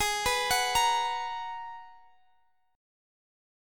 Abm9 Chord
Listen to Abm9 strummed